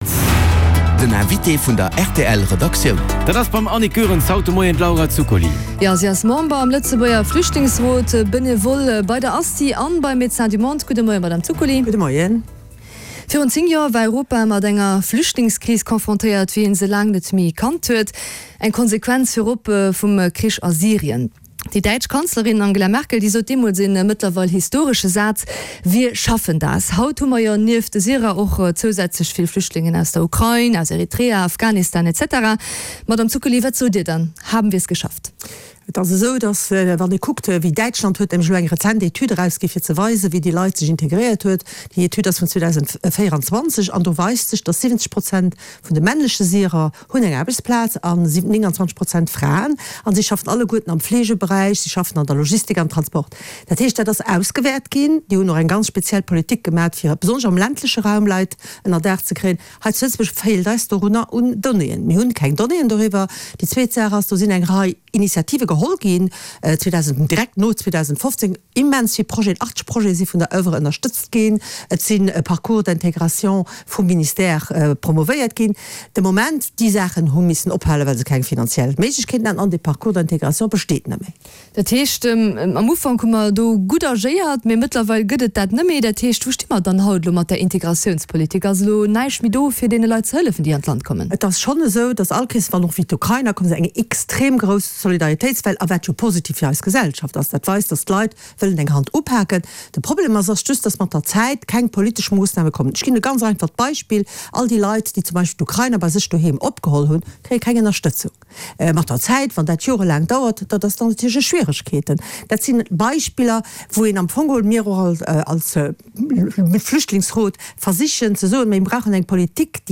Den Hannergrondinterview mat Vertrieder aus Politik an Zivilgesellschaft